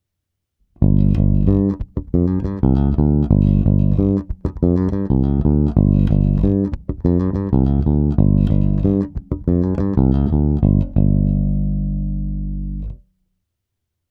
čistý zvuk baskytary jen prohnaný kompresorem.
Trochu špíny a prostoru.